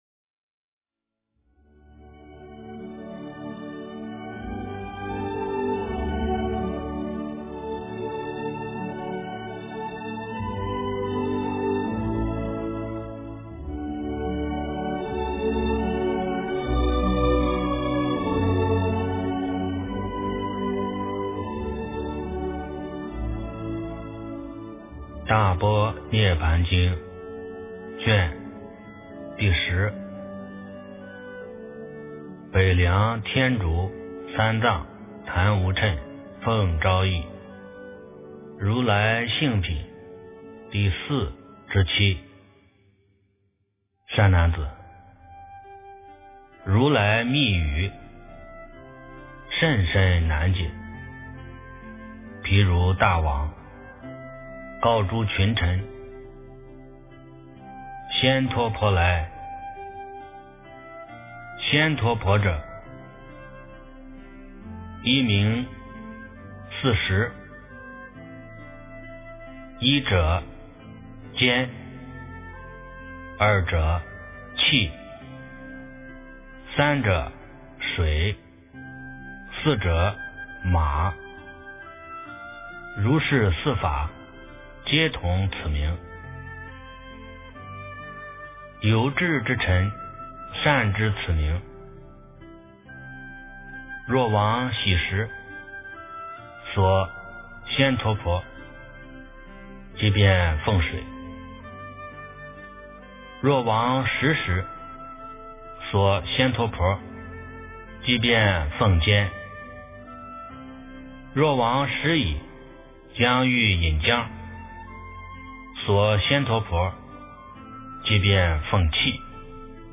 大般涅槃经10 - 诵经 - 云佛论坛